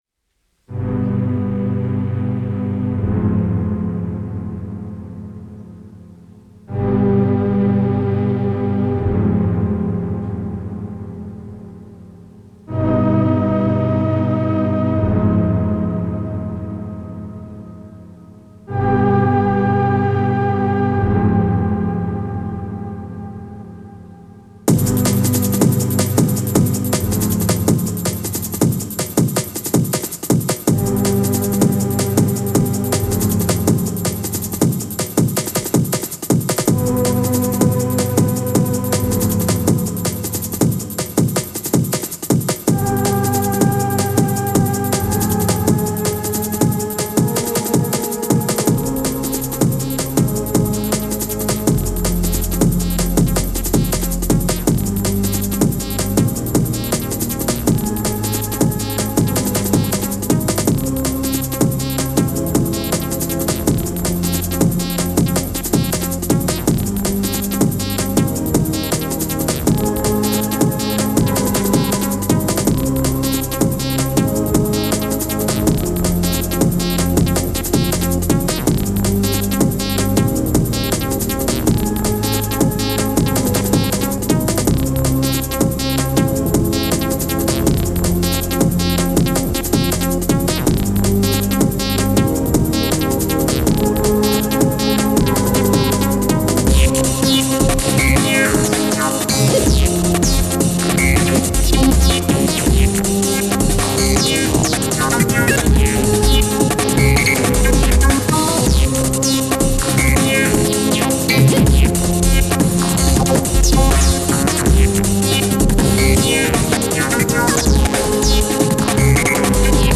Friday, March 12, 2004 Royal Festival Hall, London
electronica